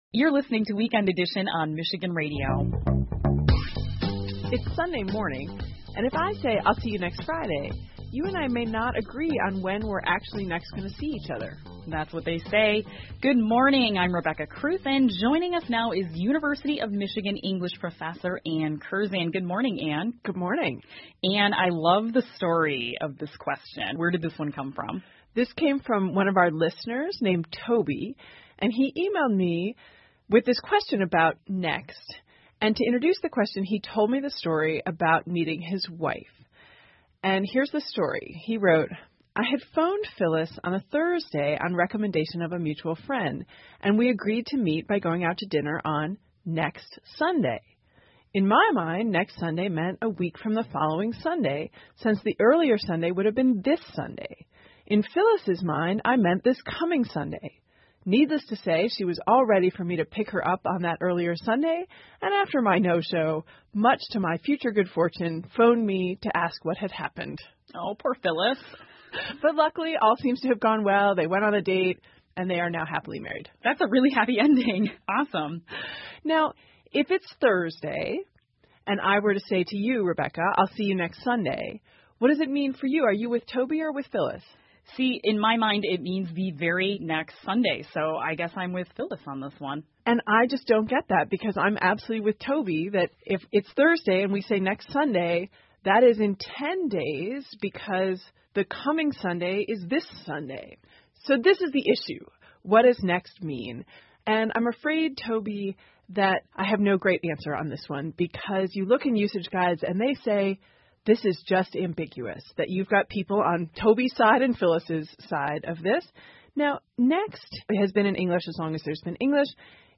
密歇根新闻广播 一场约会引起的纠纷:英语中 听力文件下载—在线英语听力室